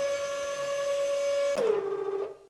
winchsound.ogg